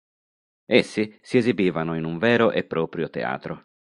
Read more Adj Adv Noun Pron Frequency A1 Hyphenated as prò‧prio Pronounced as (IPA) /ˈprɔ.prjo/ Etymology Borrowed from Latin proprius.